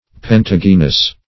Search Result for " pentagynous" : The Collaborative International Dictionary of English v.0.48: Pentagynian \Pen`ta*gyn"i*an\, Pentagynous \Pen*tag"y*nous\, a. (Bot.)